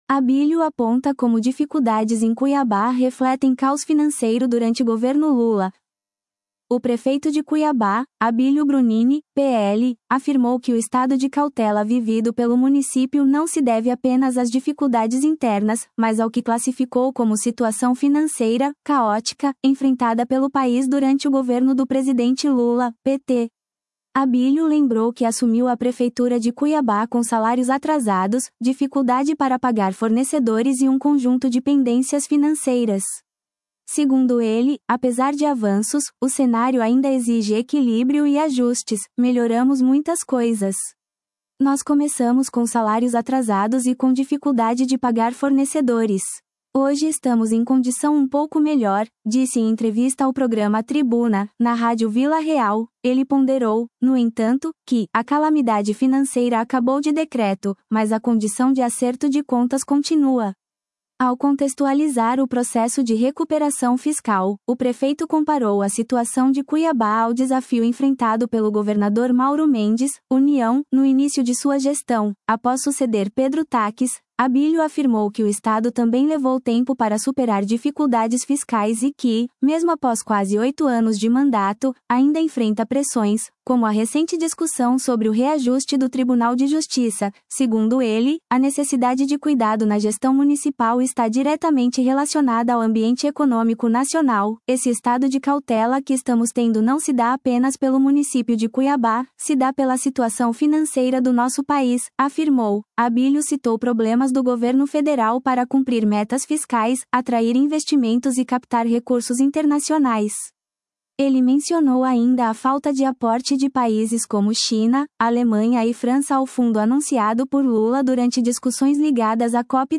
“Melhoramos muitas coisas. Nós começamos com salários atrasados e com dificuldade de pagar fornecedores. Hoje estamos em condição um pouco melhor”, disse em entrevista ao programa Tribuna, na rádio Vila Real.